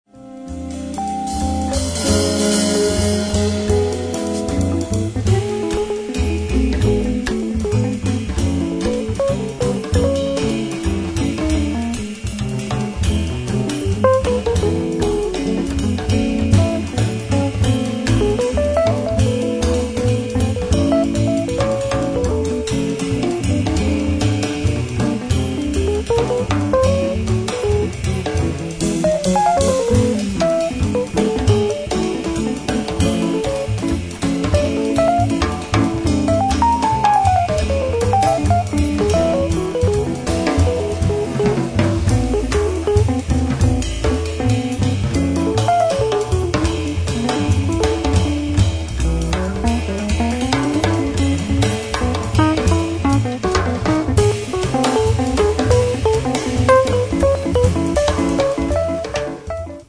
alto e soprano sax
Classic and electric guitars
piano, fender rhodes, organ
bass
drums